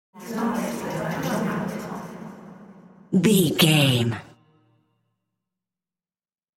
Lo Witch Chatting.
Sound Effects
Atonal
ominous
eerie
spooky